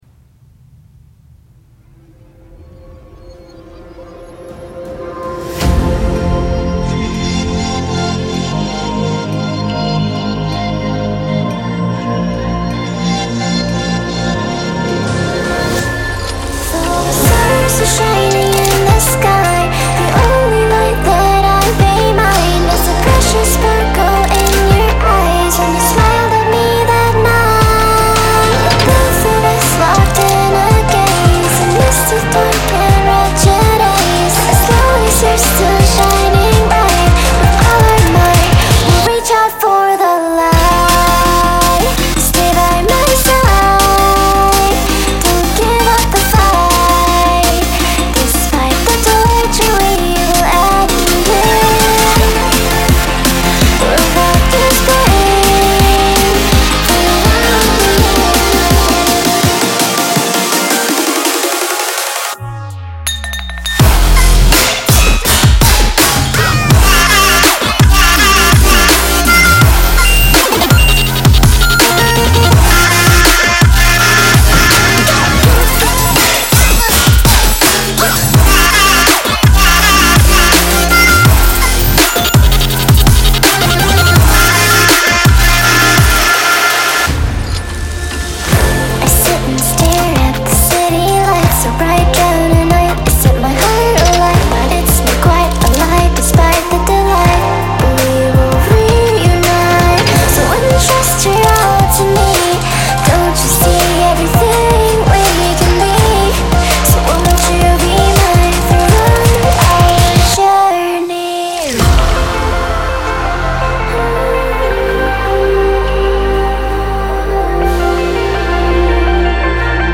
BPM82-82
Audio QualityPerfect (High Quality)
Full Length Song (not arcade length cut)